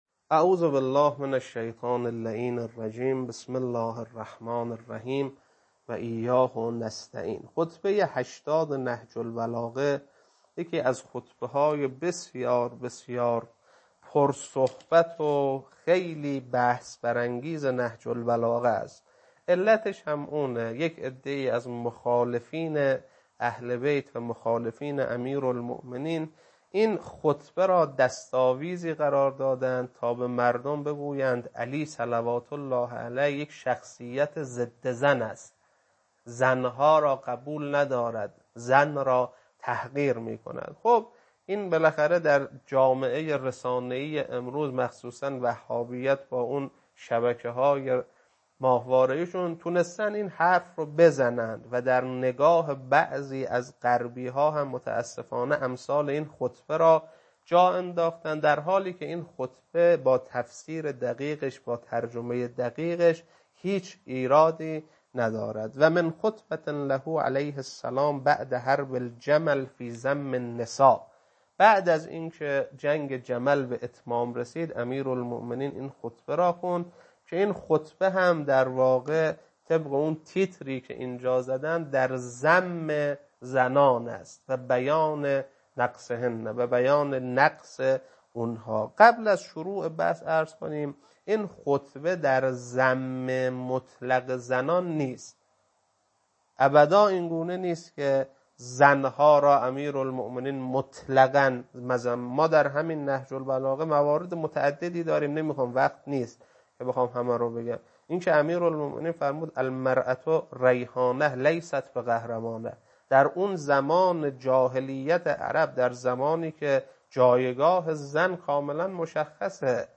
خطبه 80.mp3